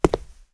Footstep01.wav